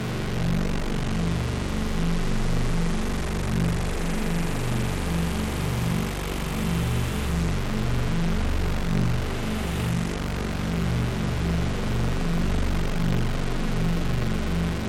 На этой странице собраны загадочные звуки порталов — от металлических резонансов до глубоких пространственных эффектов.
Звук вибрирующего портала